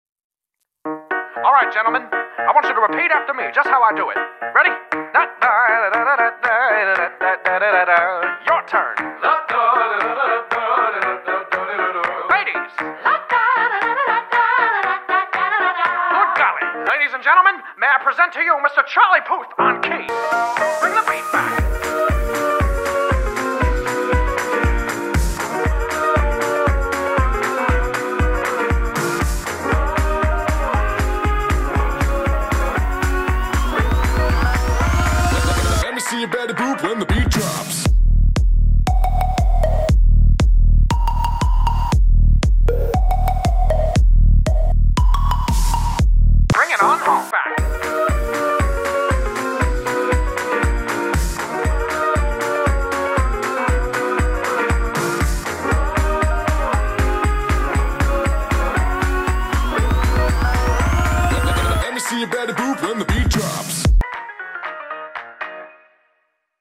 Betty Boop, Electro Swing